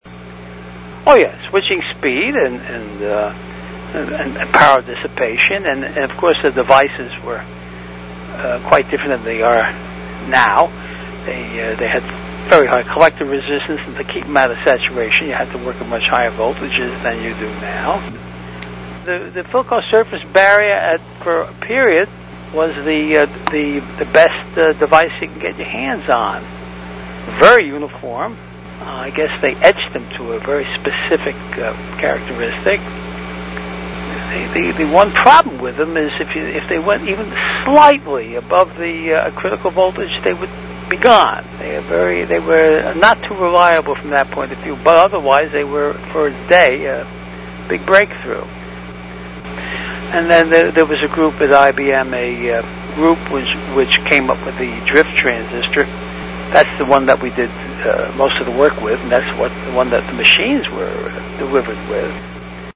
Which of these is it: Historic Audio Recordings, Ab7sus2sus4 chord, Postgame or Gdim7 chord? Historic Audio Recordings